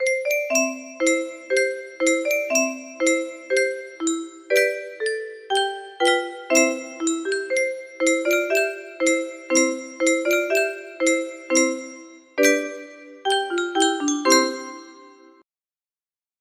Petit Escargot music box melody